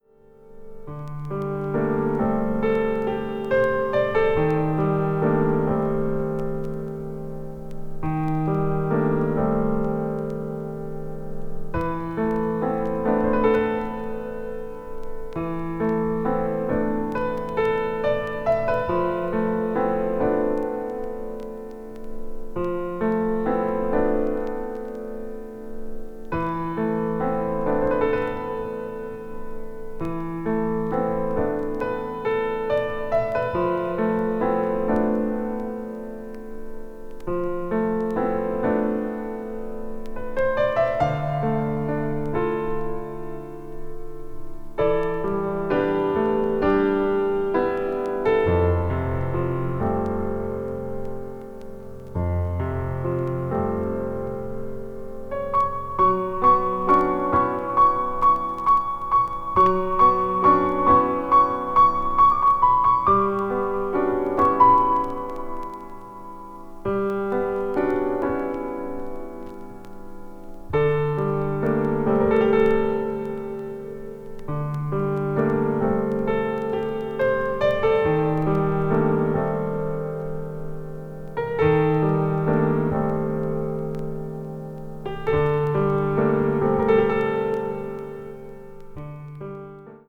わずかにチリノイズが入る箇所あり